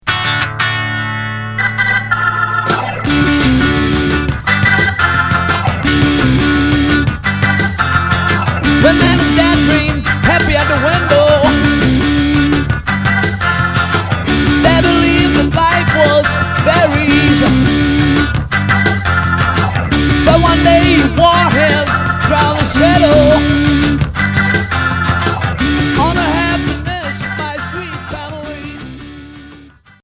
Pop garage Unique 45t retour à l'accueil